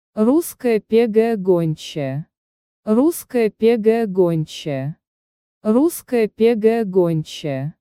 Say it in Russian: